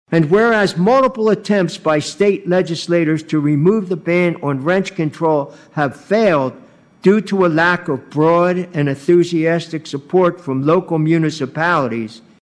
Commissioner Don Cooney read the resolution.